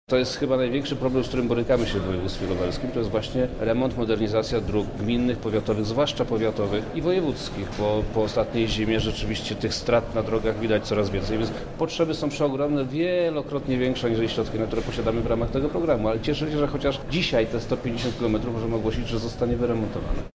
K22_B430B163963B45B191C676AAD35CCF09– mówi Przemysław Czarnek – wojewoda lubelski.